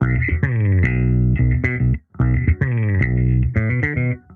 Index of /musicradar/sampled-funk-soul-samples/110bpm/Bass
SSF_JBassProc1_110B.wav